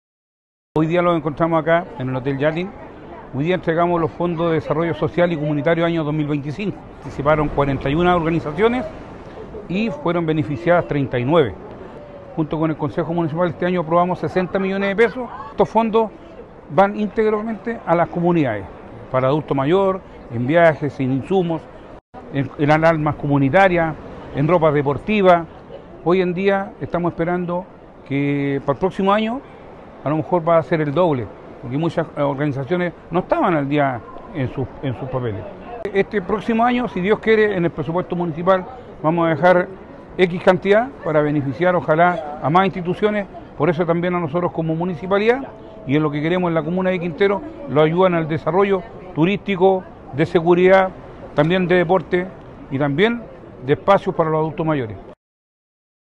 El miércoles 15 de octubre se realizó la ceremonia de cierre del Fondo Municipal de Desarrollo Social y Comunitario 2025, que permitirá la ejecución de 38 proyectos impulsados por organizaciones sociales de Quintero, con una inversión cercana a los $60 millones de pesos.